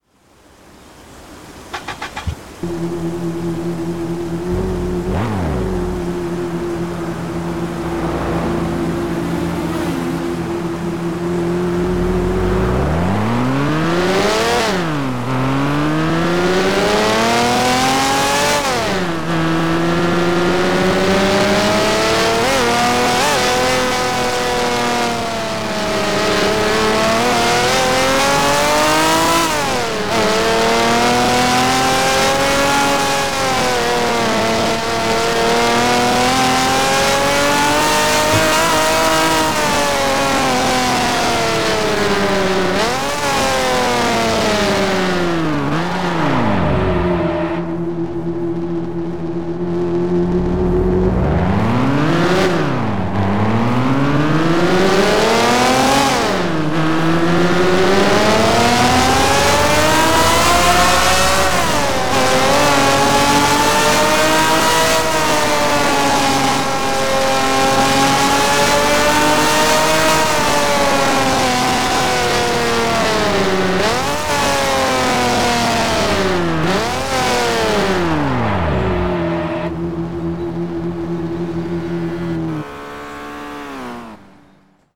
- Aston Martin DBR9